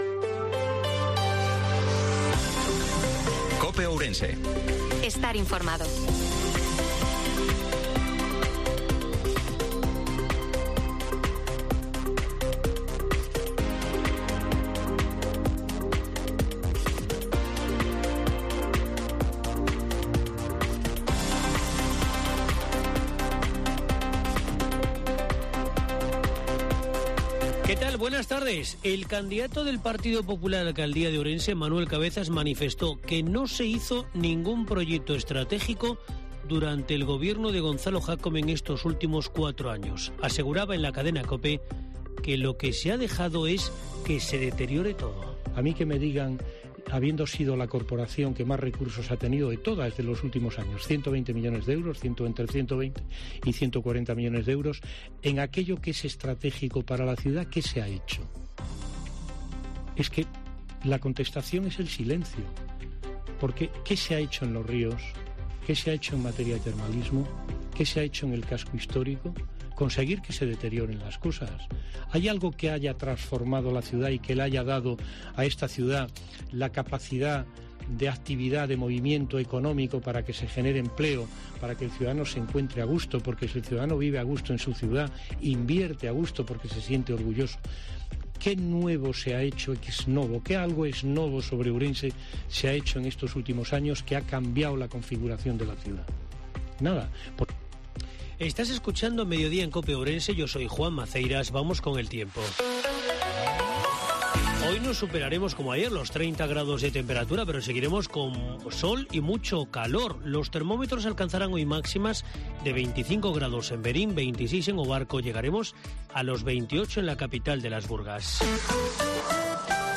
INFORMATIVO MEDIODIA COPE OURENSE-18/04/2023